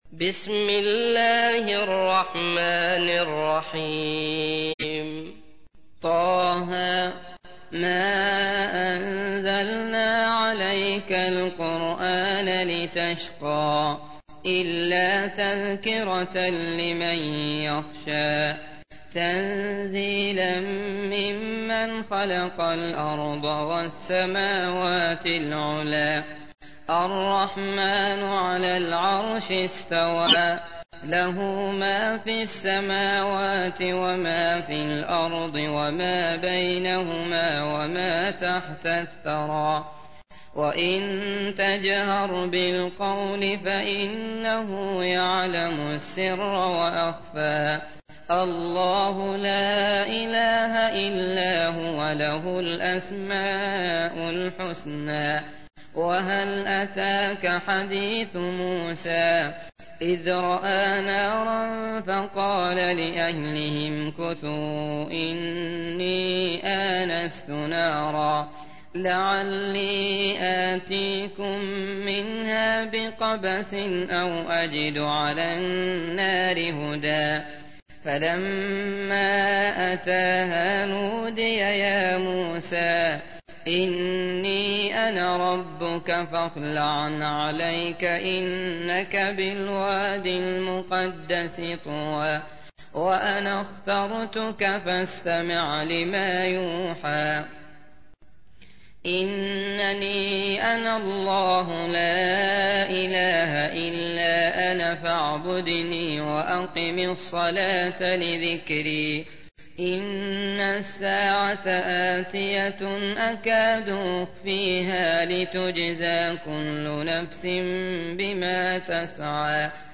تحميل : 20. سورة طه / القارئ عبد الله المطرود / القرآن الكريم / موقع يا حسين